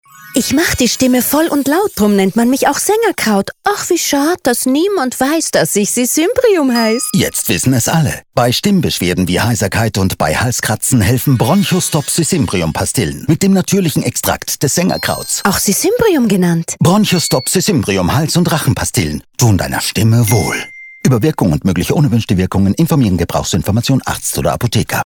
05 Hörfunkspot „Sisymbrium Bronchostop“